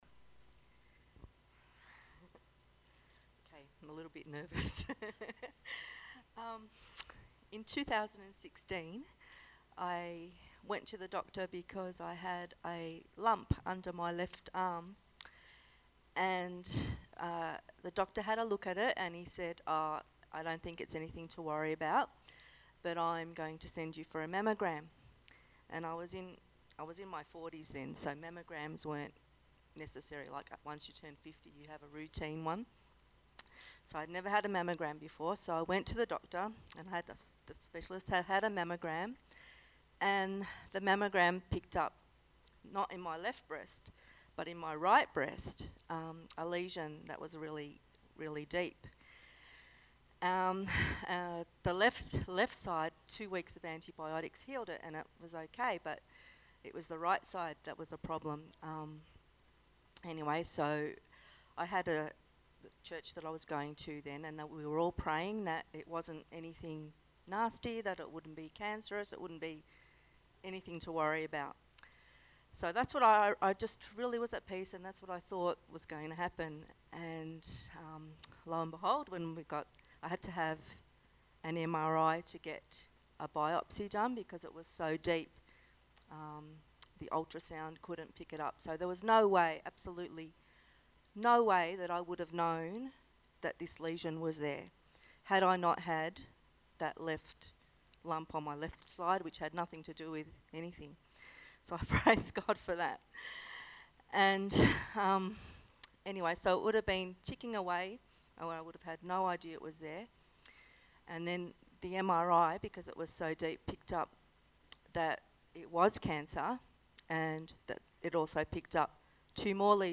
Testimony